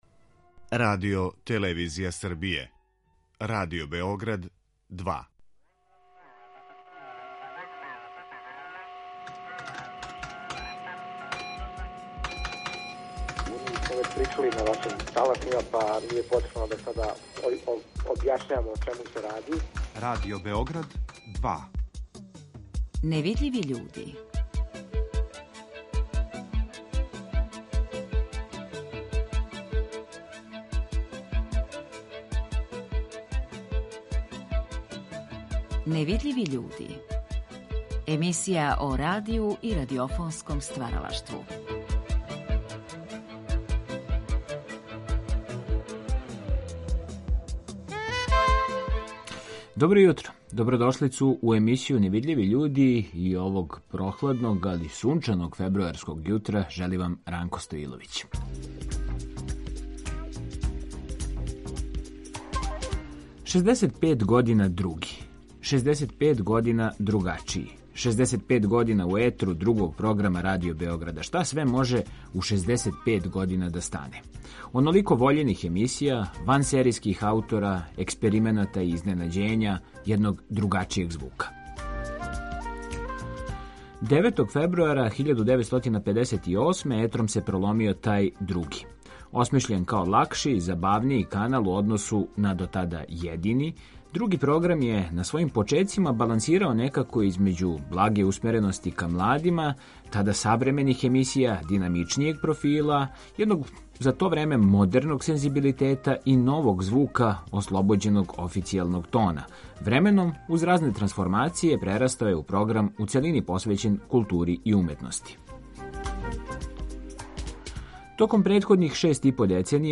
емитујемо одломке из разговора